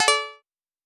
huuray.wav